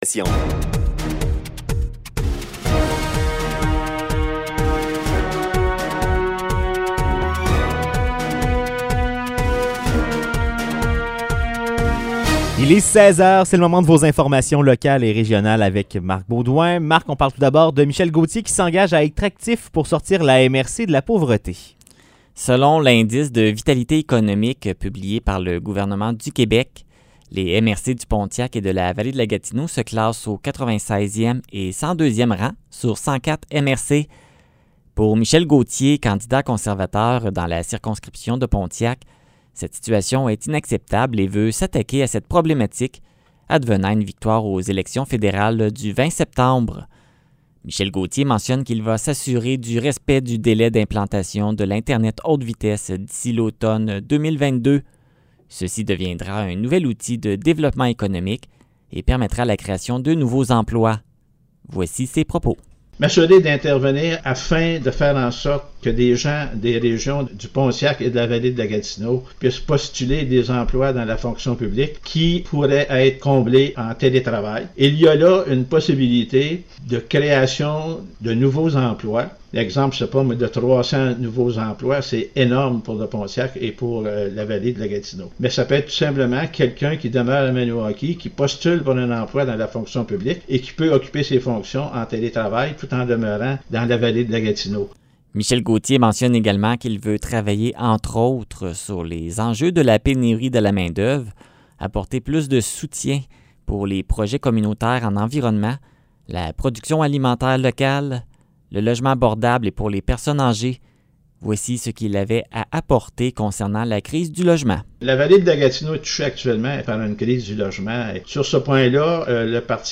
Nouvelles locales - 7 septembre 2021 - 16 h